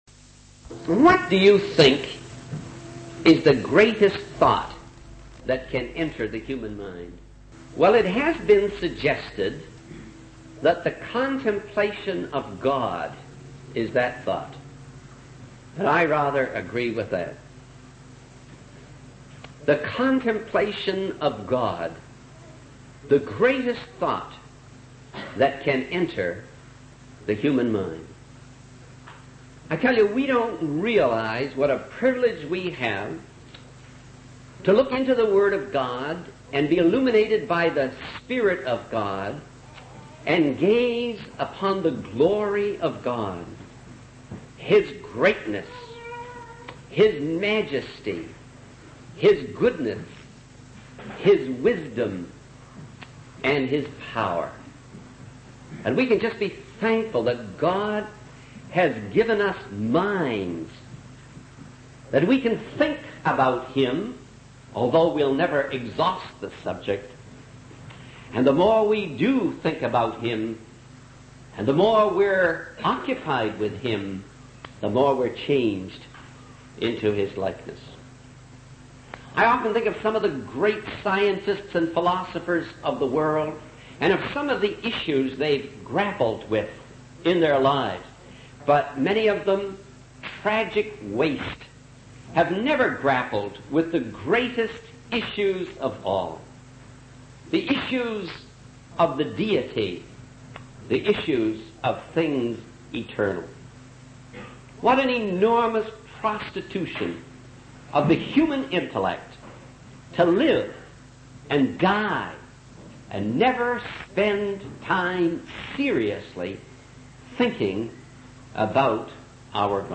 In this sermon, the speaker begins by acknowledging the doubts and uncertainties that people may have about how anything good can come out of certain situations.